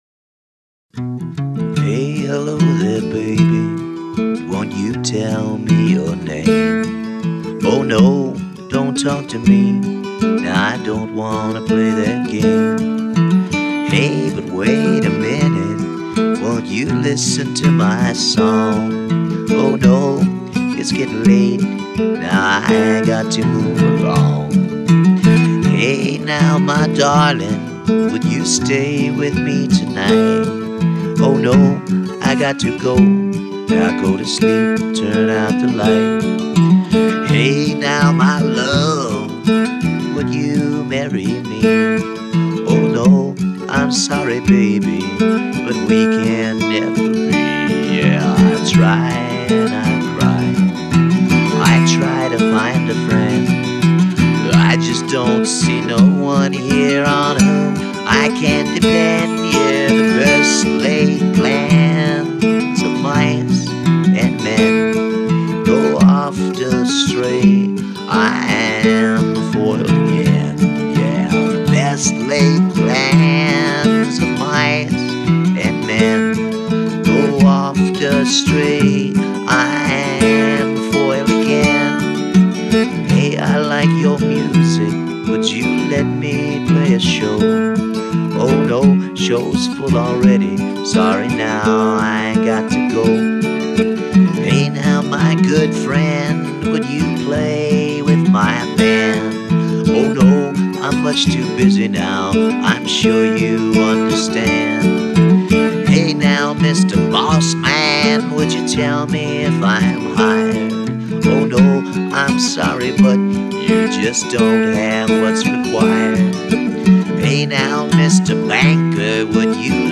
Demos and live songs: